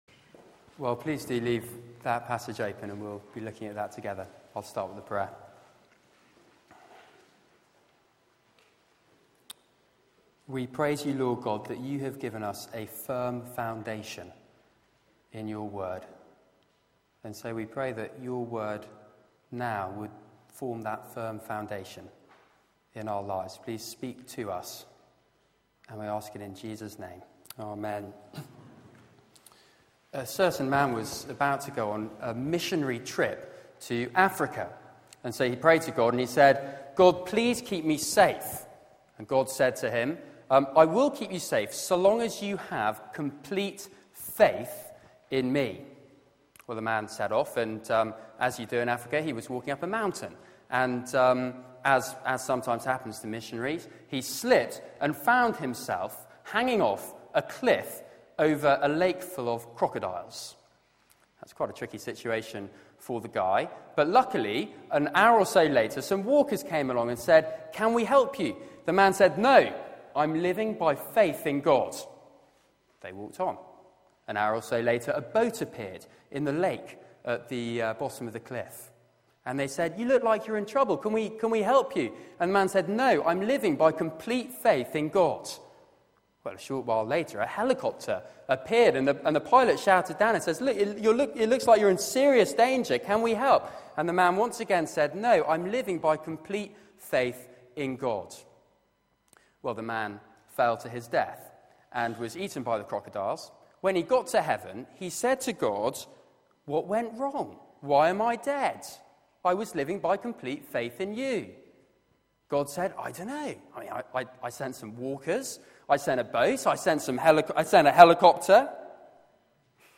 Media for 6:30pm Service on Sun 05th Jan 2014 18:30 Speaker
Passage: Hebrews 11:1-22 Series: Jesus is better Theme: What faith is Sermon